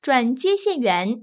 ivr-speak_to_a_customer_service_representative.wav